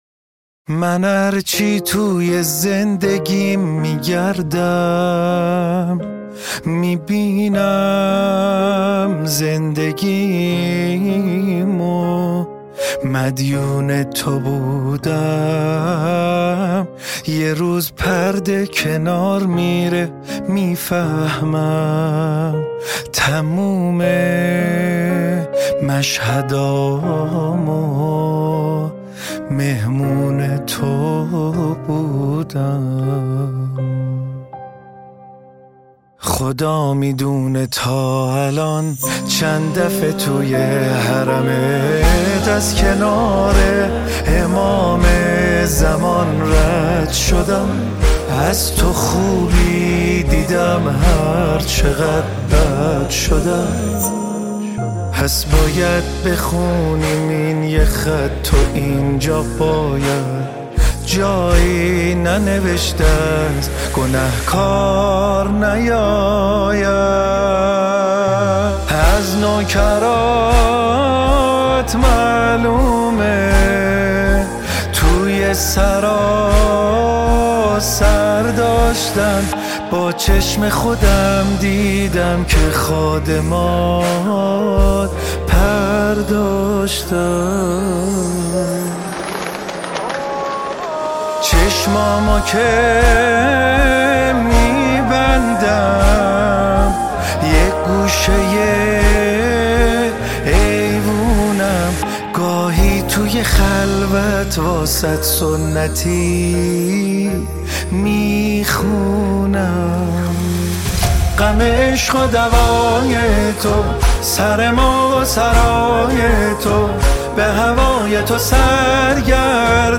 با صدای روحانی و گرم
ژانر: آهنگ